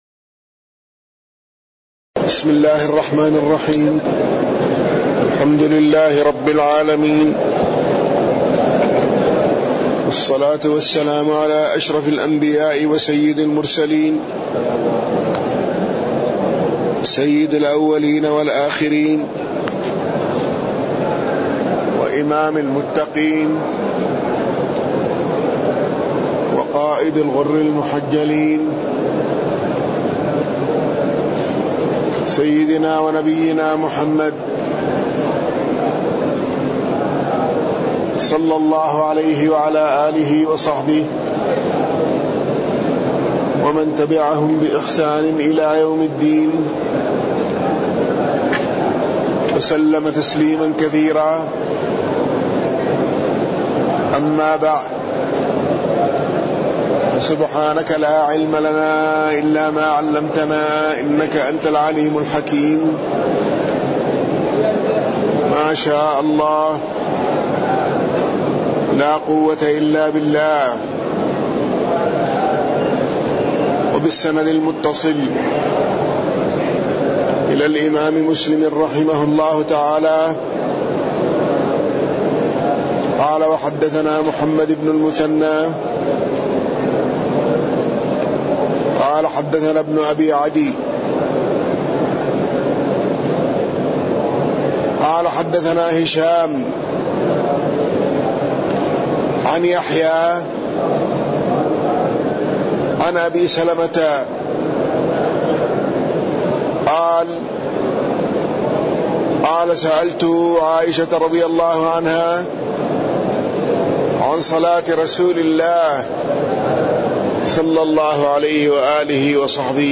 تاريخ النشر ١٧ رمضان ١٤٣٠ هـ المكان: المسجد النبوي الشيخ